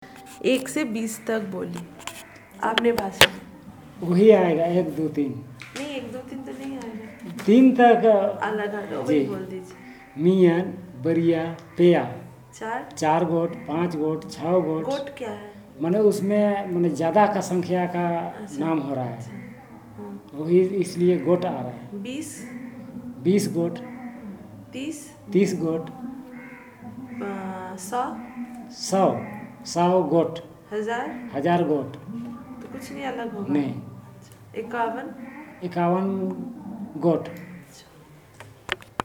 NotesThis is an elicitation of words abbout numbers using Hindi as the language of input from the researcher's side, which the informant then translates to the language of interest